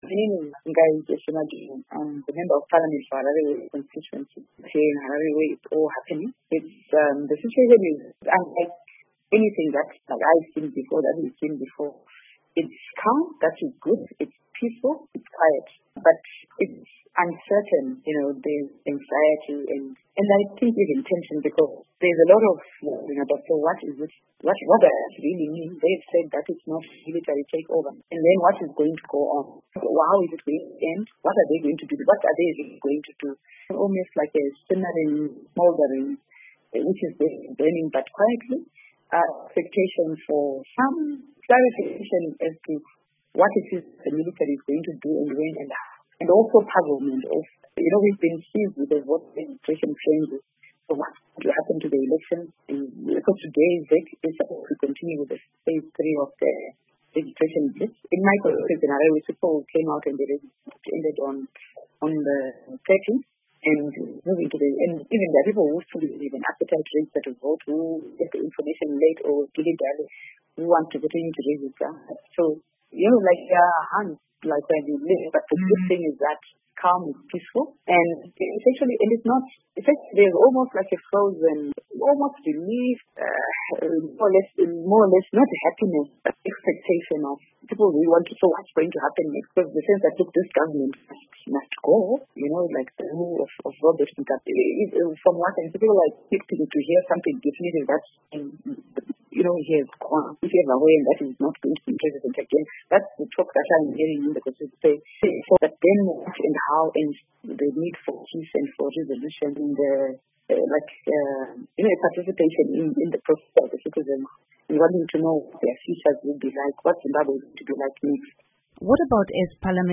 Member of Parliament for the MDC-T formation, Jesse Majome, says her Harare West constituency and many other areas in the country are tense.